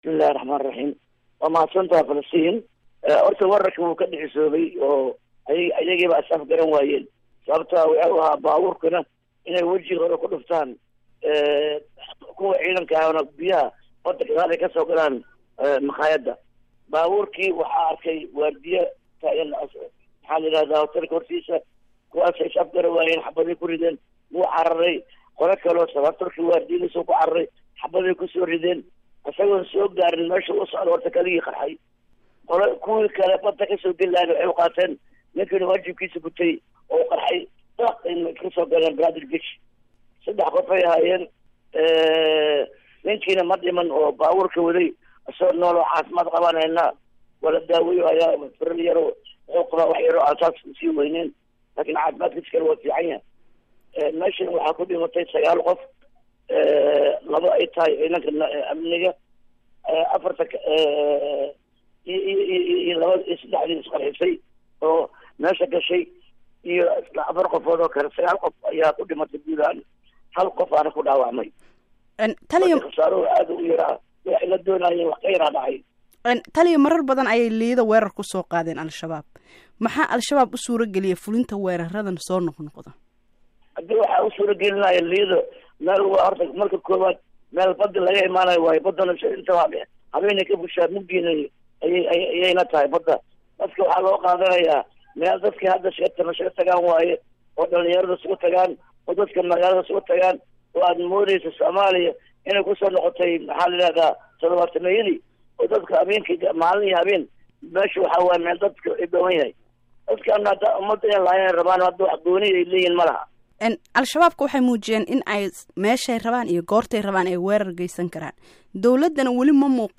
Wareysi: Jeneraal Gaafow